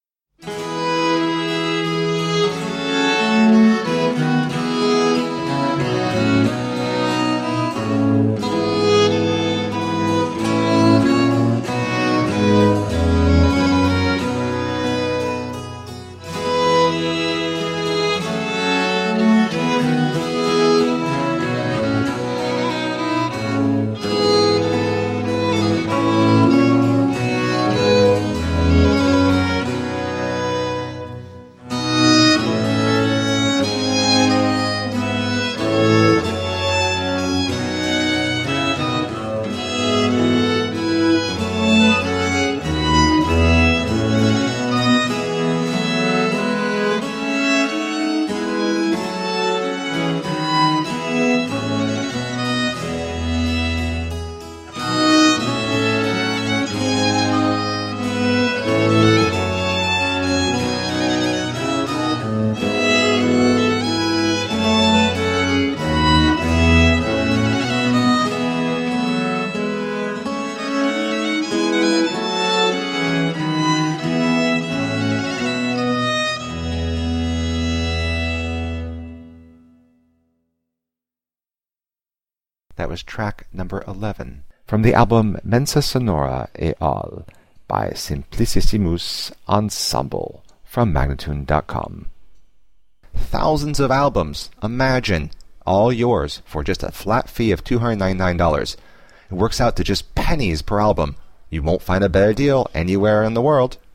17th and 18th century classical music on period instruments.